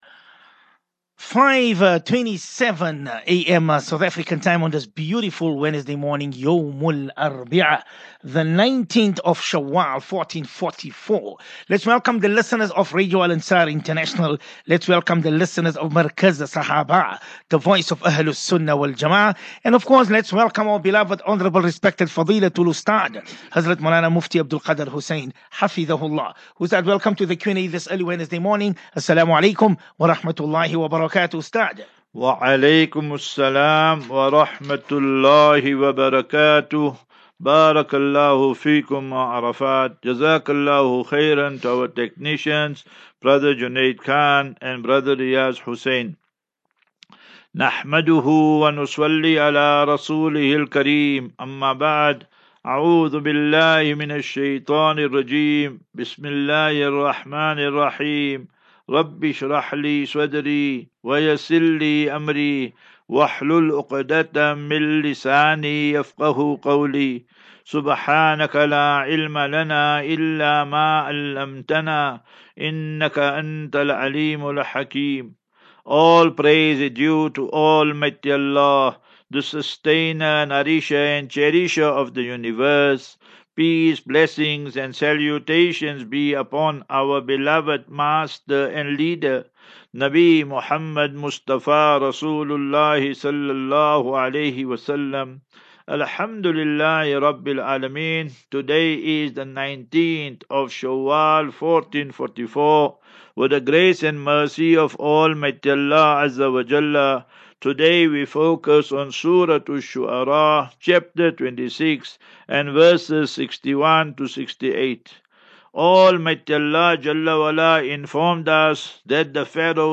Daily Naseeha..